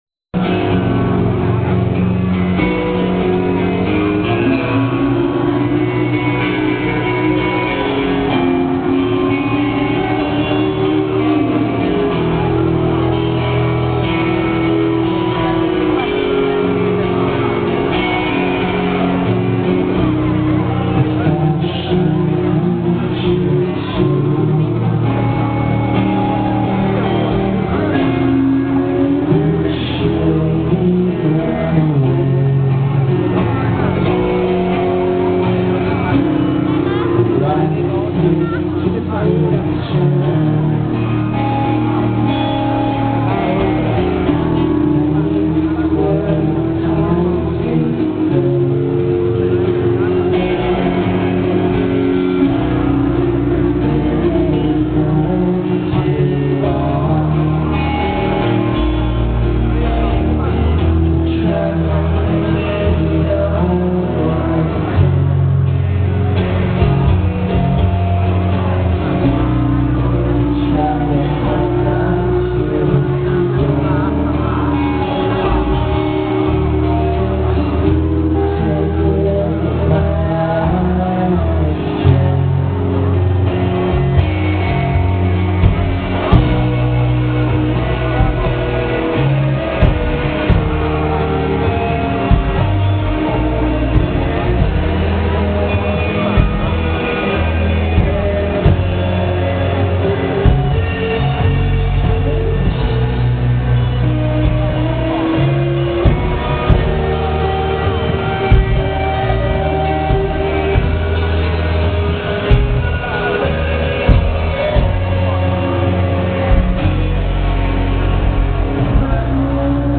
¬mp3 live:
- scusate la qualità del suono..